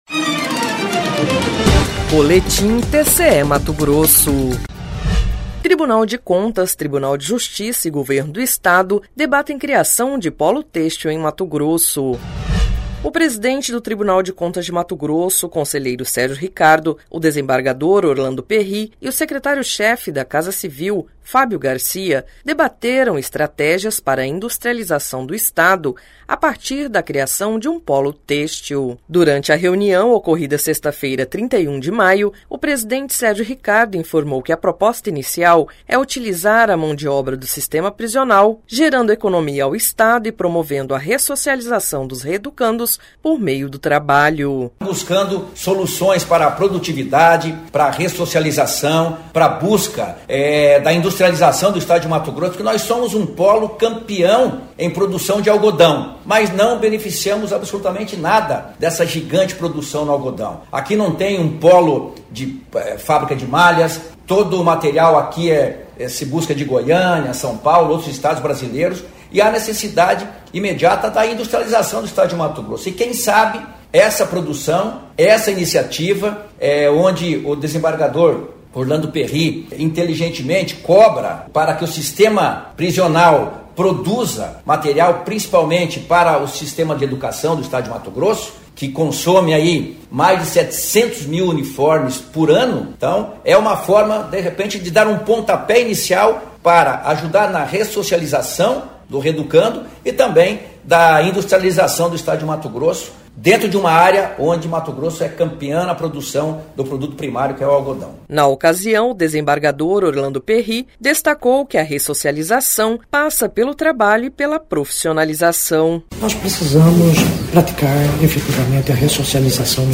Sonora: Sérgio Ricardo – conselheiro-presidente do TCE-MT
Sonora: Orlando Perri – desembargador em MT
Sonora: Fábio Garcia - secretário-chefe da Casa Civil de MT